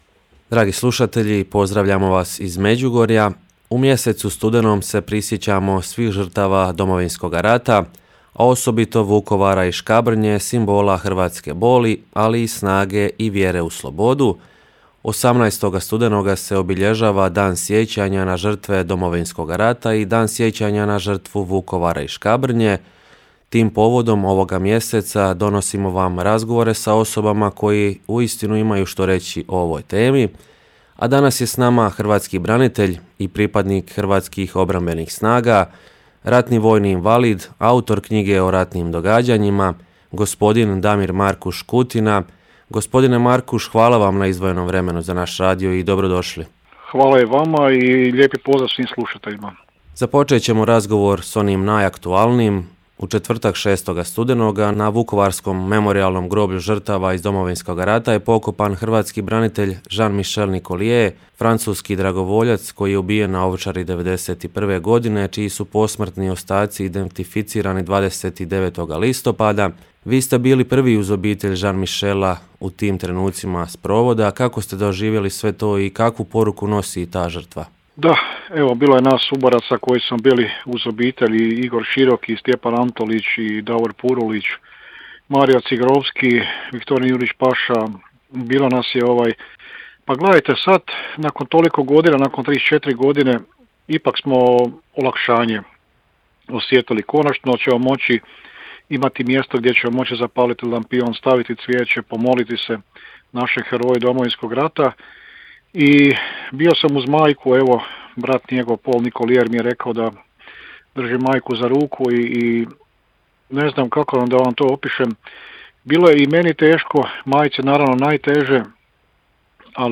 U mjesecu studenom se prisjećamo svih žrtava Domovinskog rata, a osobito Vukovara i Škabrnje, simbola hrvatske boli, ali i snage i vjere u slobodu. 18. studenoga se obilježava Dan sjećanja na žrtve Domovinskog rata i Dan sjećanja na žrtvu Vukovara i Škabrnje. Tim povodom ovoga mjeseca u programu našega radija donosimo vam razgovore s osobama koje uistinu imaju što reći o ovoj temi.